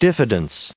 1747_diffidence.ogg